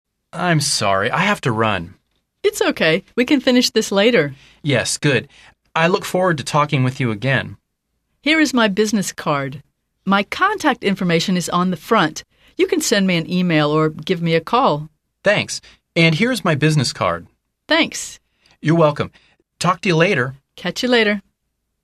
兩個工作夥伴談話到一半，有一方就急急忙忙得要先離開，於是兩個人趕緊交換一下聯絡方式，讓這個話題可以延續下去。